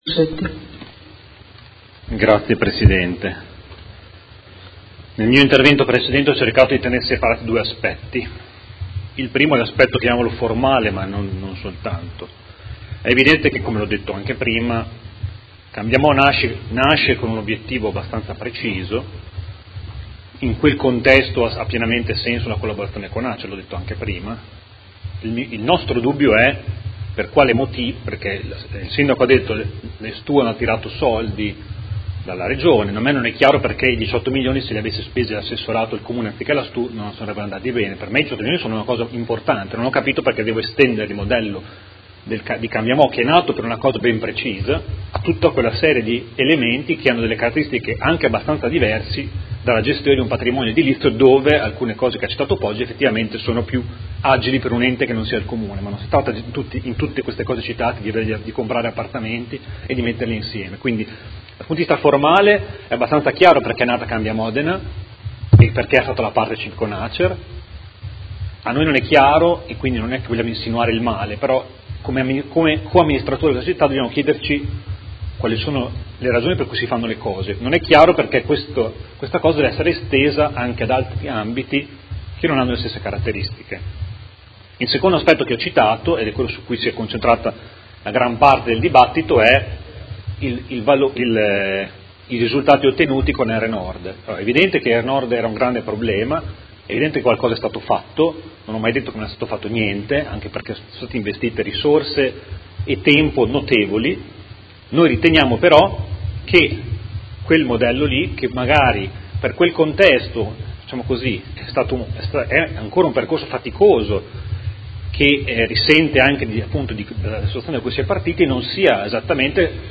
Seduta del 23/11/2017 Dichiarazione di voto.